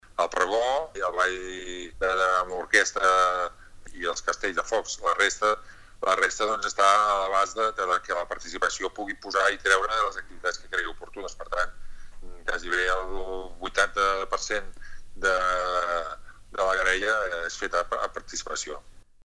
El regidor de Cultura, Francesc Subirats, destaca que els veïns tenen un ampli marge per decidir la majoria d’activitats.